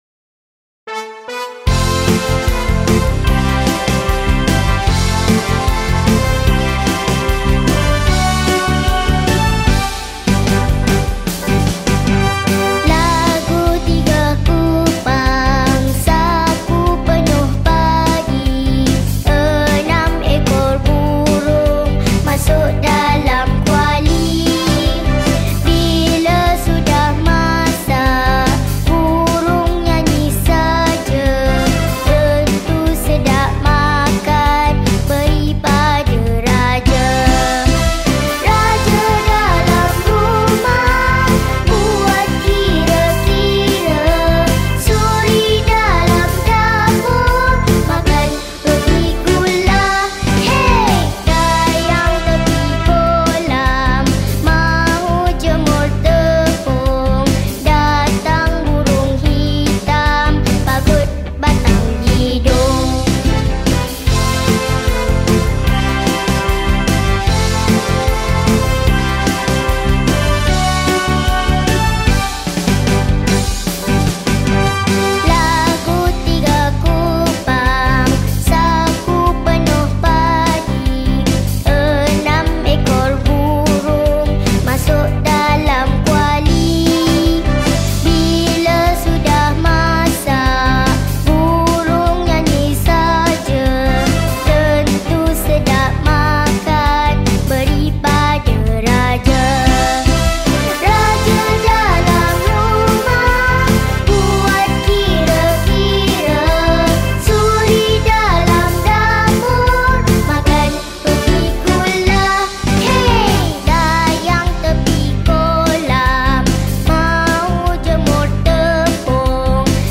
Rearranged in 2 parts harmony By
Lagu Kanak-kanak